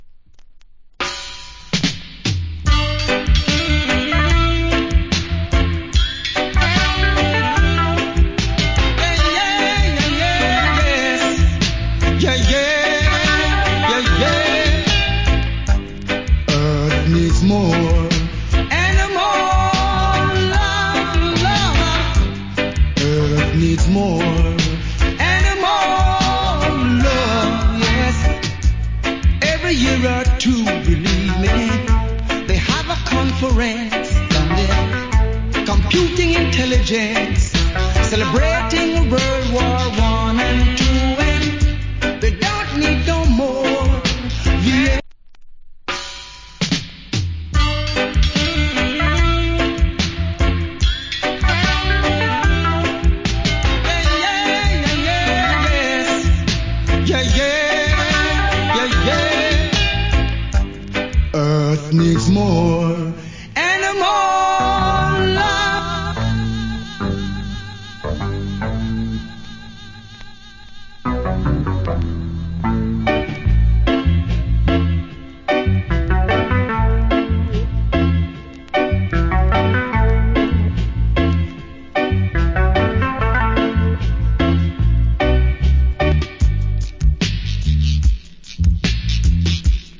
コメント 80's Cool Reggae Vocal. / Nice Dub.